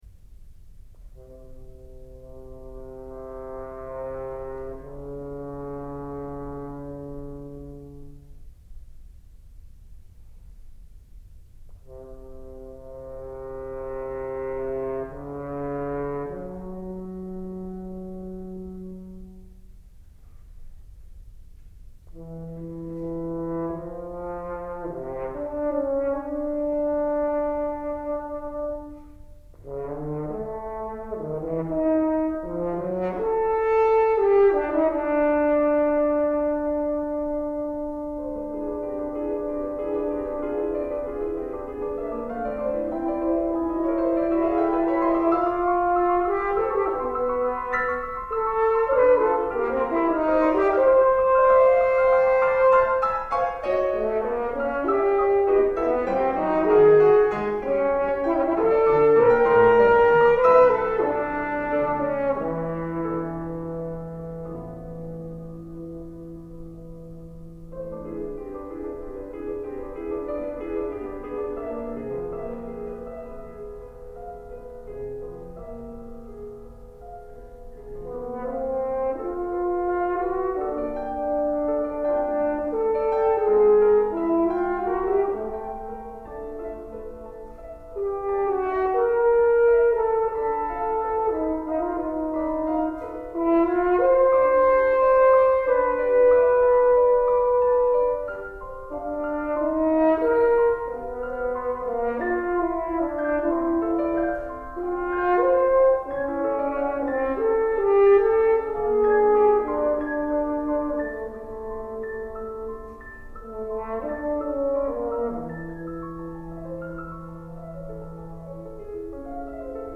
The horn writing was challenging, but also idiomatic, with contrasting melodies that I would characterize as heroic, lyrical, aggressive, and plaintive.  The piano part also embodies all of these qualities, making for a very exciting piece.  Included below is a recording of the premiere performance of Epilogue for Horn and Piano, by Roger Jones.